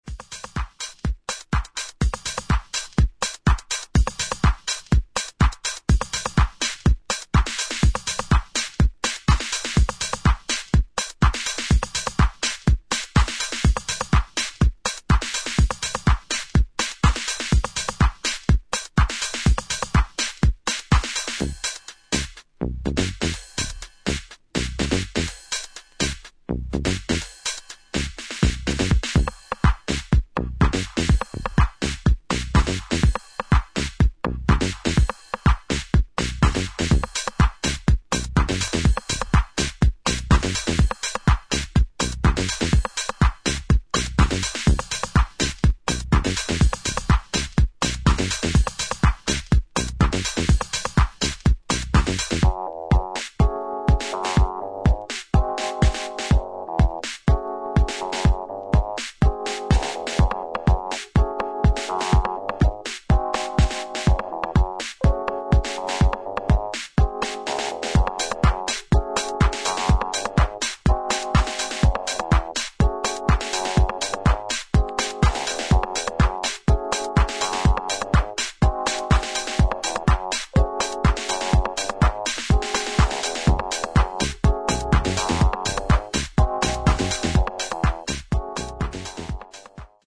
有機的な音と電子音の印象的な融合をハウスの領域で表現した一枚